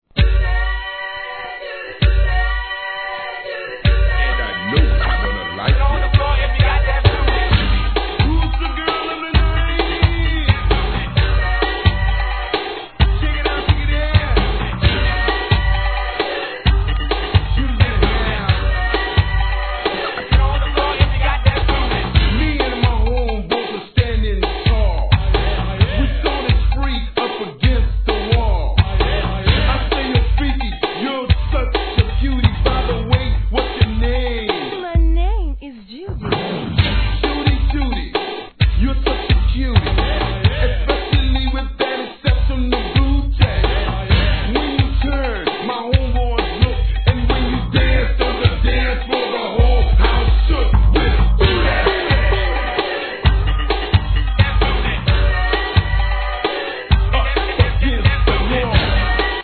HIP HOP/R&B
1991年のHIP HOUSE!!